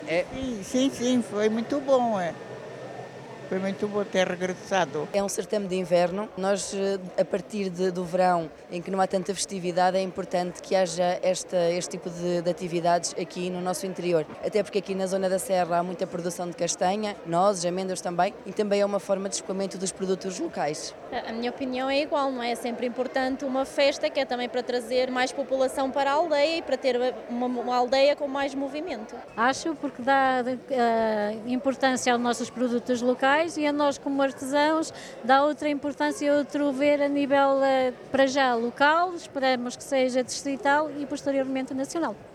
O evento contou com cerca de 30 expositores, oriundos não só do concelho, mas também de várias regiões do país, que consideram o regresso da iniciativa muito positivo: